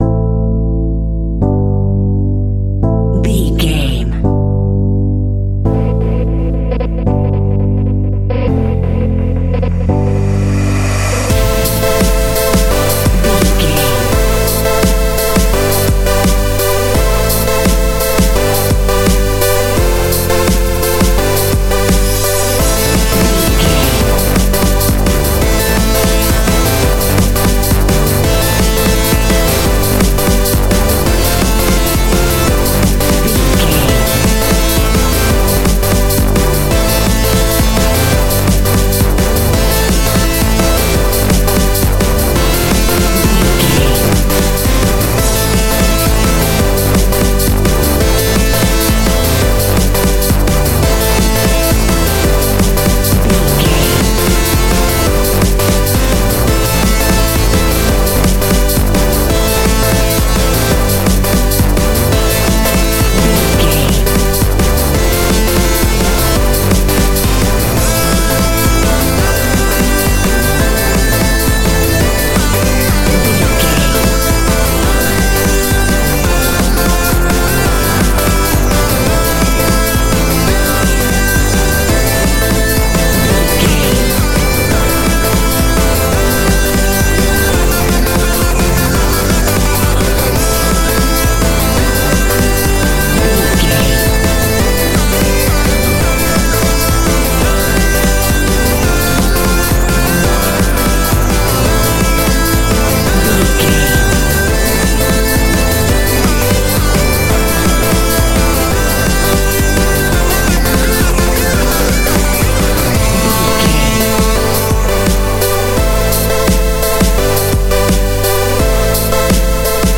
Aeolian/Minor
Fast
driving
intense
powerful
energetic
electric piano
synthesiser
drum machine
electronic
sub bass
synth leads